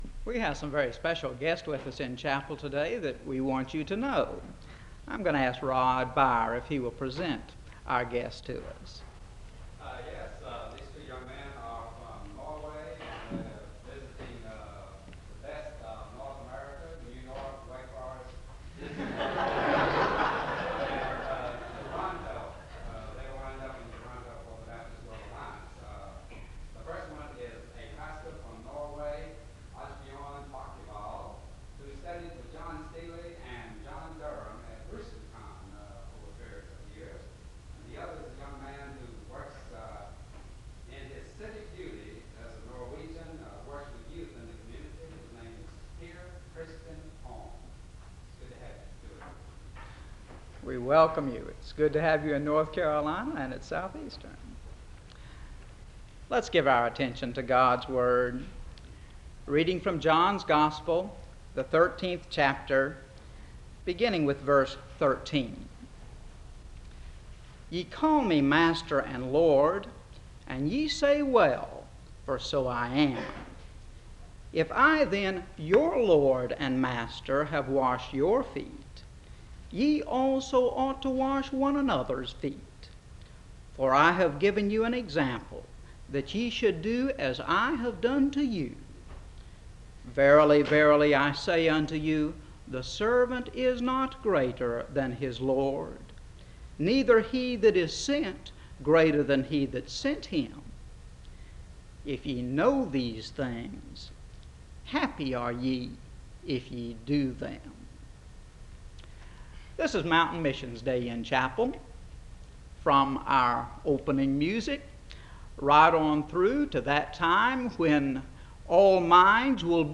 The service opens with a presentation of special guests from Norway (00:00-00:54).